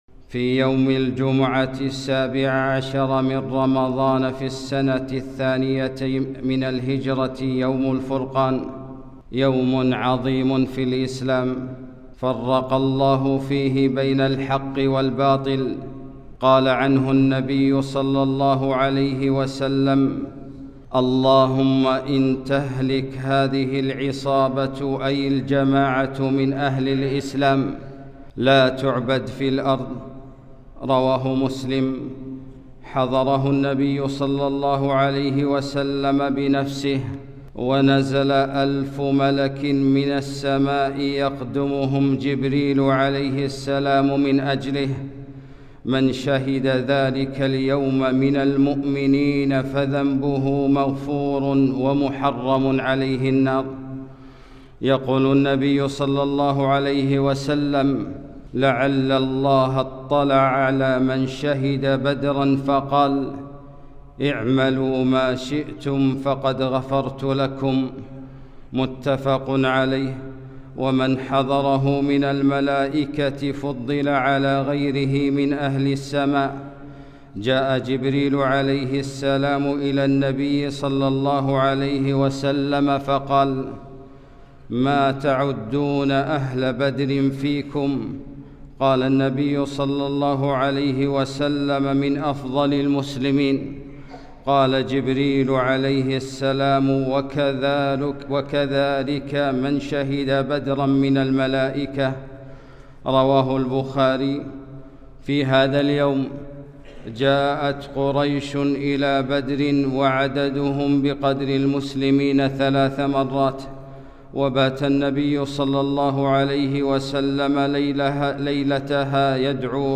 خطبة - يوم الفرقان